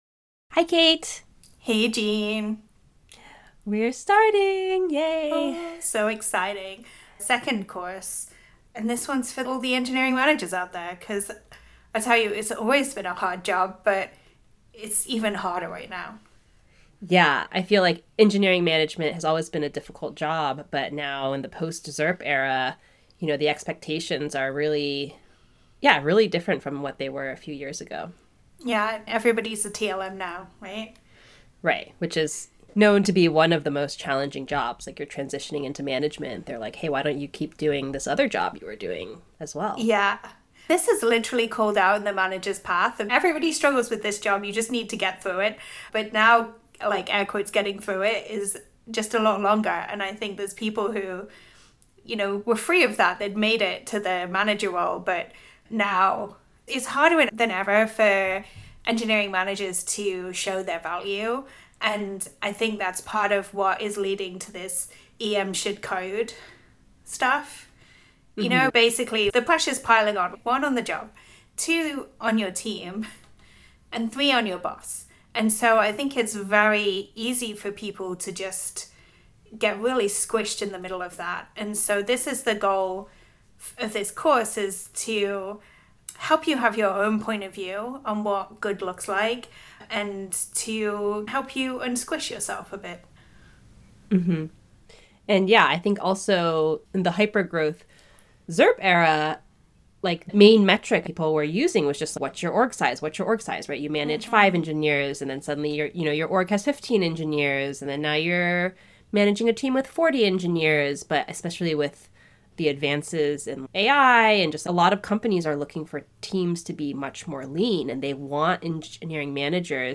Audio Conversation